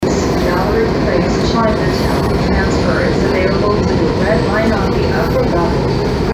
gallery-place-chiantown-arrival.mp3